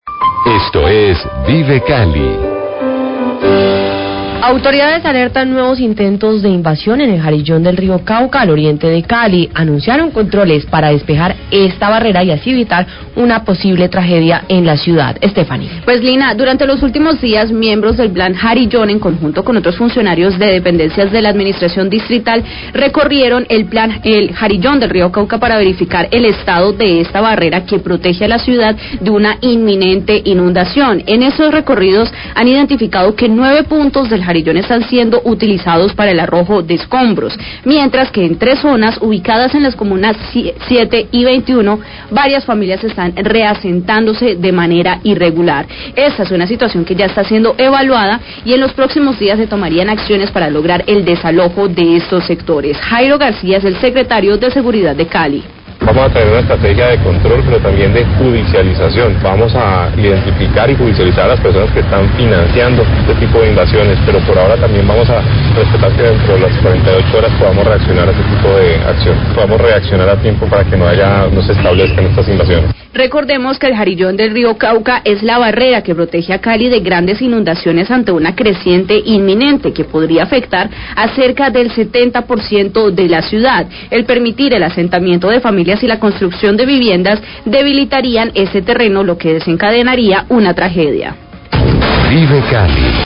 Radio
Las autoridades caleñas alertan por nuevos intentos de invasión de predios en el jarillón del Río Cauca, luego que miembros del Plan Jarillón, en conjunto con funcionarios de la administración munciipal, realizaron un recorrido por la zona. Jairo Garcia,  Secretario de Seguridda de Cali, habla de los operativos en está área.